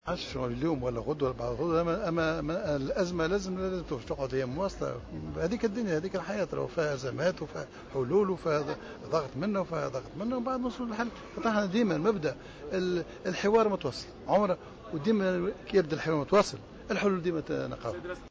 علّق رئيس الحكومة الحبيب الصيد اليوم لدى افتتاحه الصالون الوطني للبيئة على إضراب المعلمين ومقاطعتهم للامتحانات.